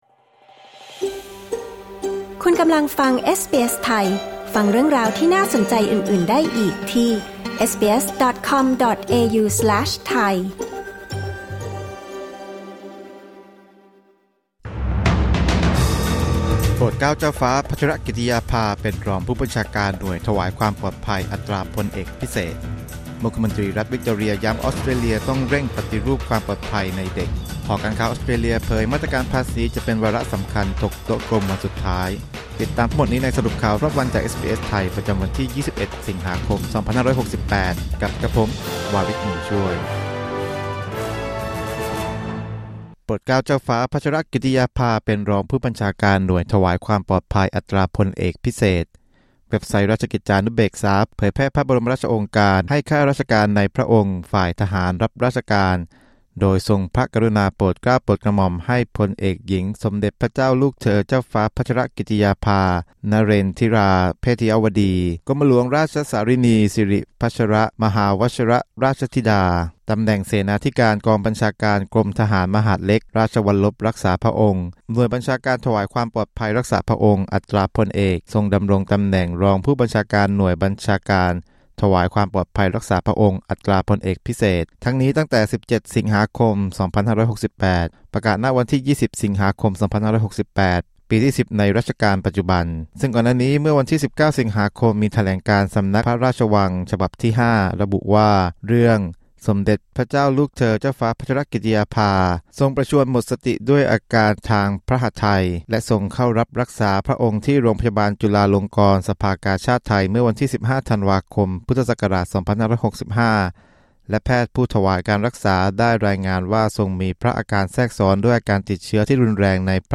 สรุปข่าวรอบวัน จาก เอสบีเอส ไทย Credit: Timon Reinhard via Unsplash, SBS Thai